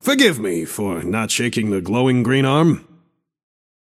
Shopkeeper voice line - Forgive me, for not shaking the glowing, green arm.